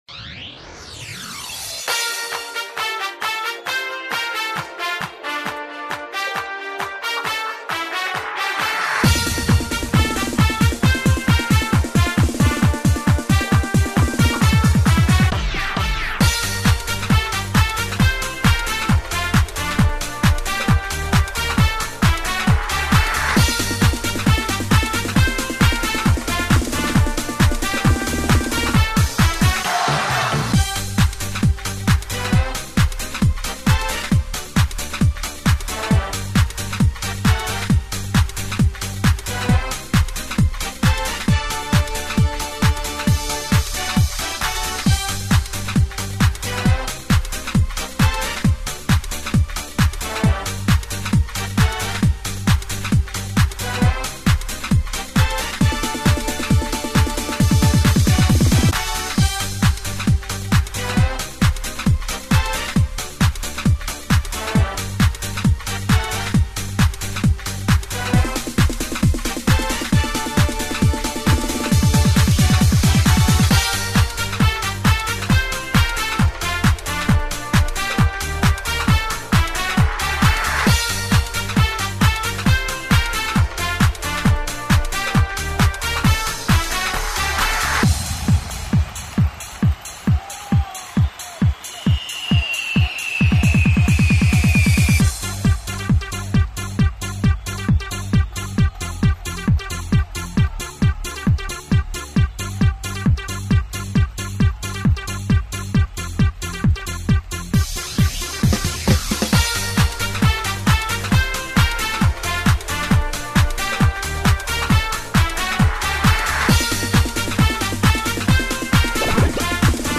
Предлагаем вам музыкальное сопровождение для выполнения упражнений.
Утренняя_зарядка_старшая_группа.mp3